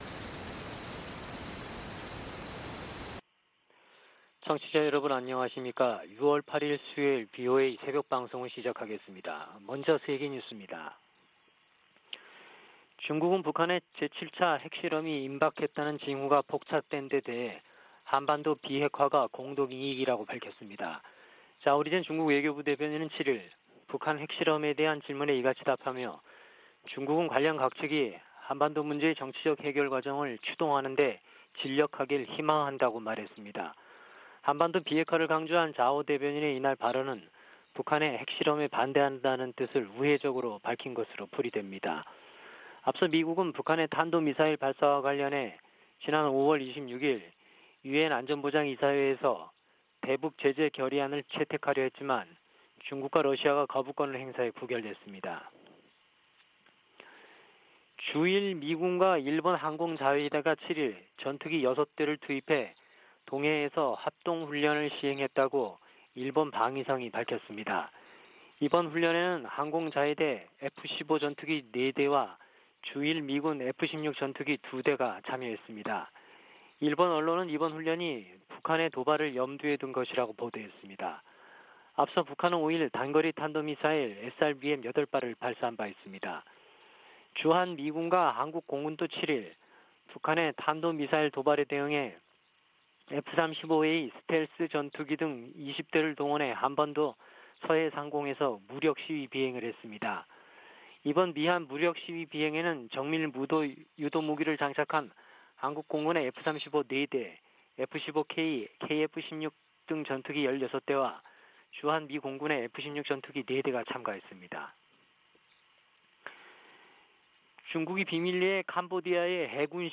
VOA 한국어 '출발 뉴스 쇼', 2022년 6월 8일 방송입니다. 북한이 7차 핵실험을 감행할 경우 미국과 한국은 신속하고 강력한 대응을 할 것이라고 서울을 방문중인 웬디 셔먼 미국 국무부 부장관이 경고했습니다. 국제원자력기구는 북한 풍계리에서 핵실험을 준비 징후를 포착했다고 밝혔습니다. 미국의 전문가들은 북한이 최근 8발의 단거리탄도미사일을 발사한 것은 전시 한국에 기습 역량을 과시하려는 것이라고 분석했습니다.